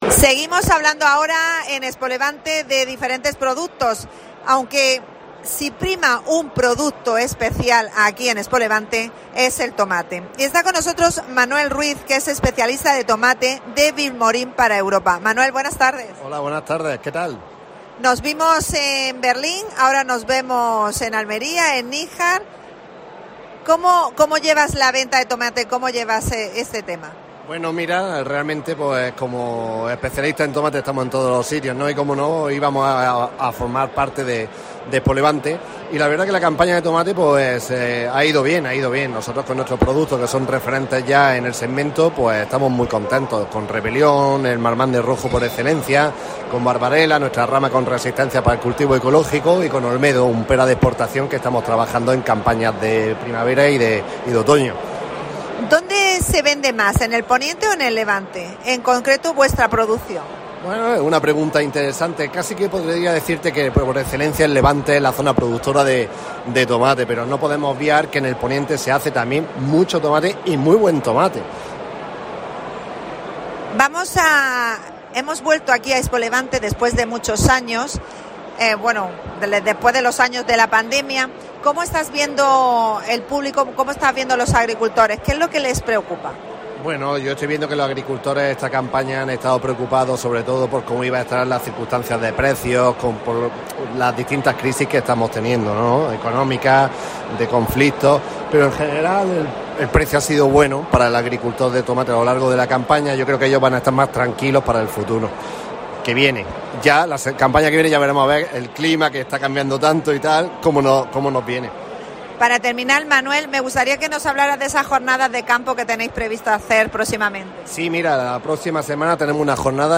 Especial ExpoLevante.